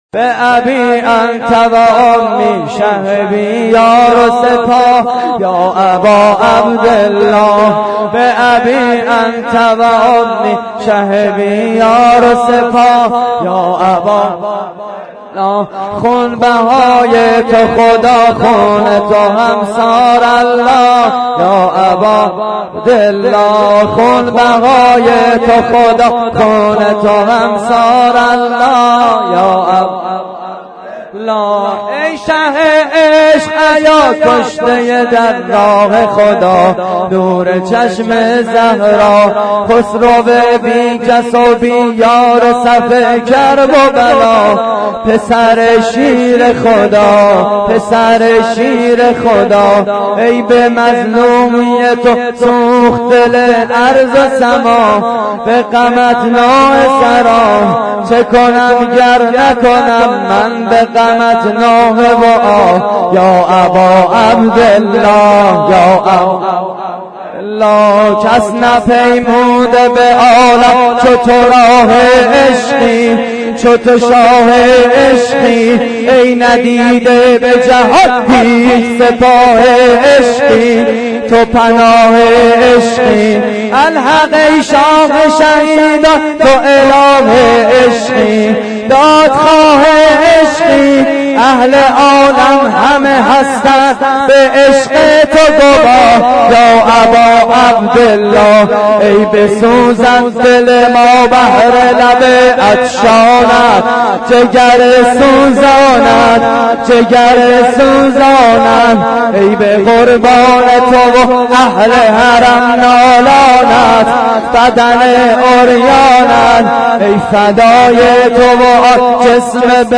مراسم عزاداری شب پنجم ماه محرم / هیئت کریم آل طاها (ع) – شهرری؛ 30 آذر 88